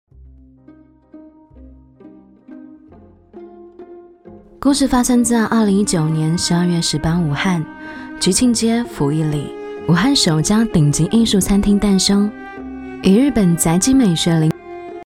女13-【台湾腔 餐厅优雅】故事发生在-台湾腔
女13旁白宣传片配音 v13
女13--台湾腔-餐厅优雅-故事发生在-台湾腔.mp3